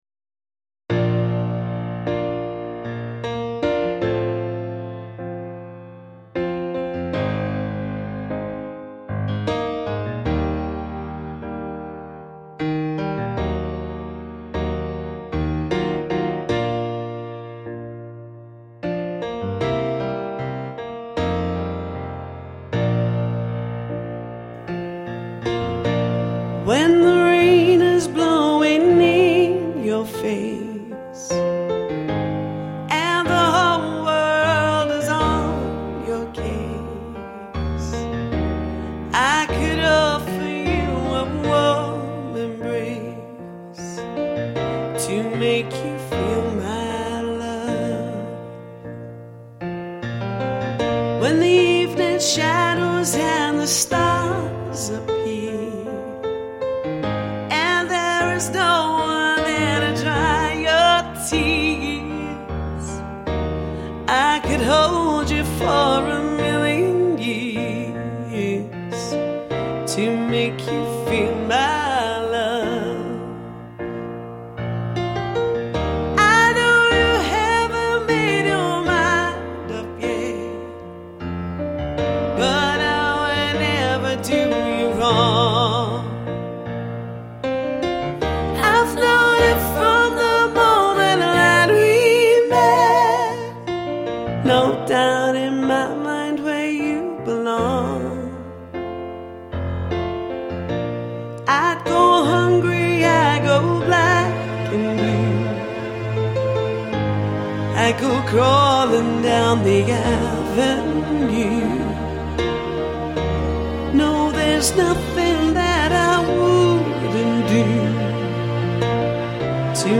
a sensational and exceptional singer
with a big voice